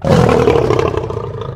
lion2.ogg